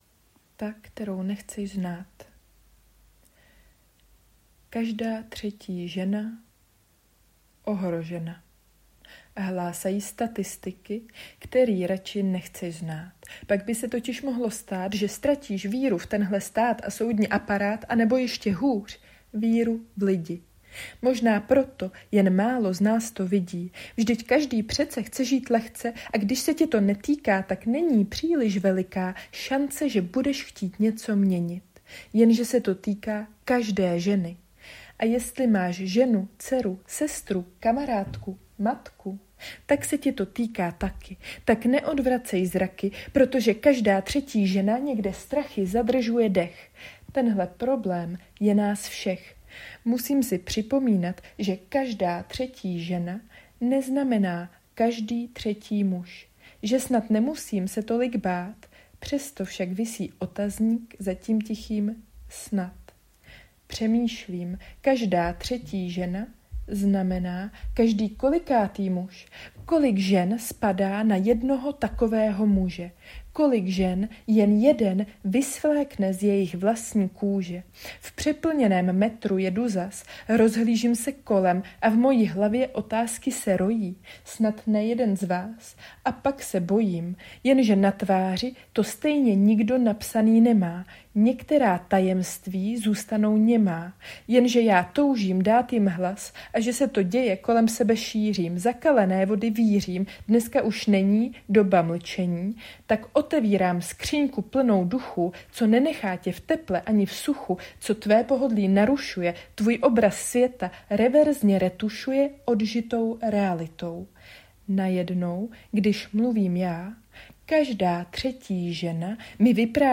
Pseudo-slam.
kategorie básně/experiment